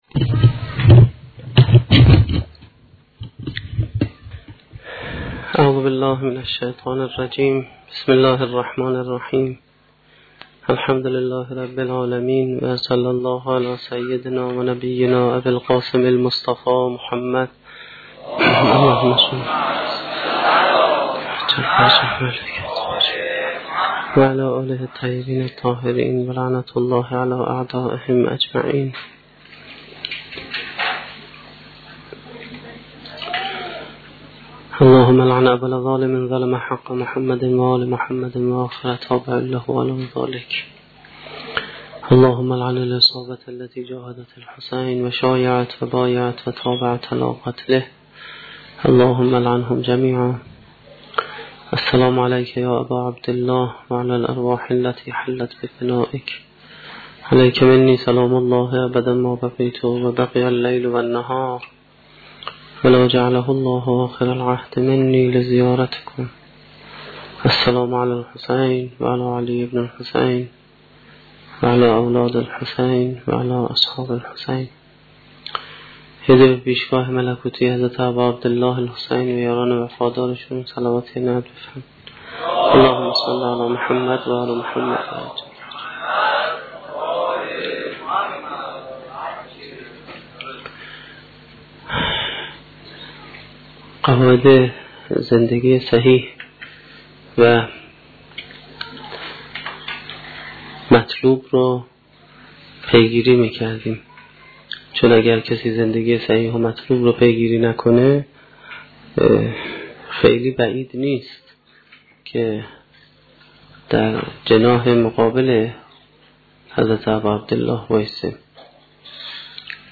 سخنرانی ششمین شب دهه محرم1435-1392